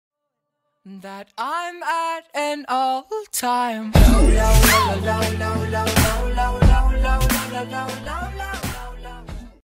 free-follower-sound-for-twitch_i7QbtMD.mp3